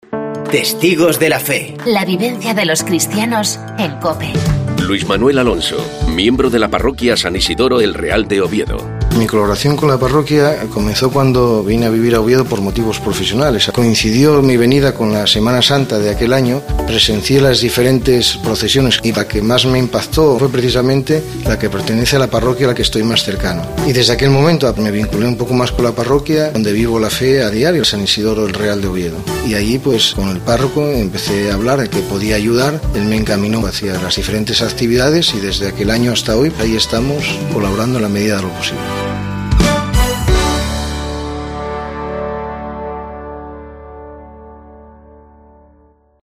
Hoy escuchamos el testimonio